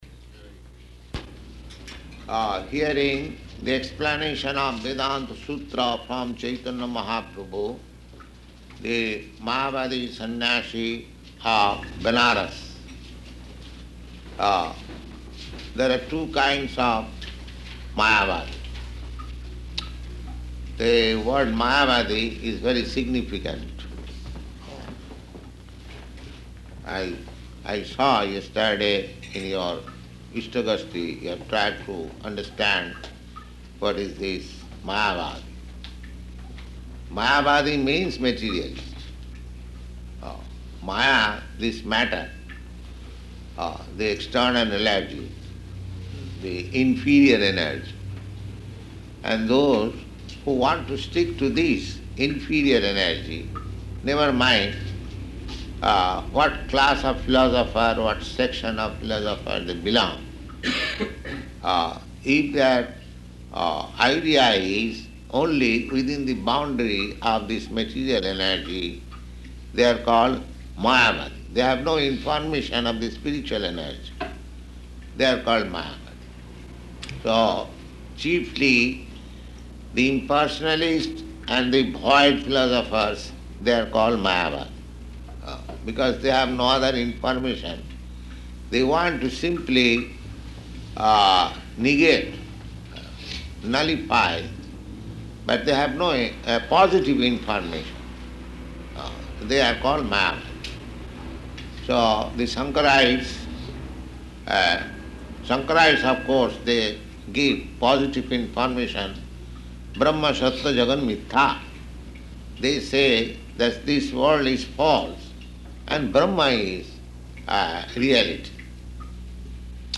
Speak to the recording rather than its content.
Location: San Francisco